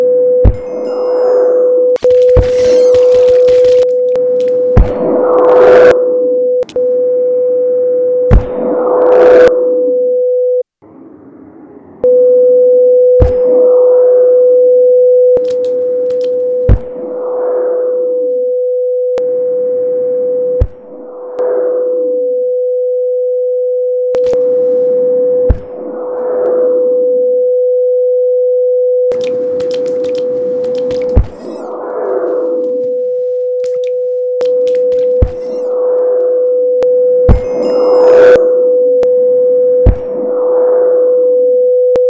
1. After the guardian kill sound is played, every sound in the system is attenuated. Then over a short period, the sound is gradually going back to normal level
2. Apart from the attenuation in point 1, the sound then gradually amplifies further, beyond the normal level it was before the guardian kill sound was played. Then, the sound instantly goes back to normal level
A 500 Hz signal sine was played all the time with occasional guardian death sounds to show the distortions.